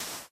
sand1.mp3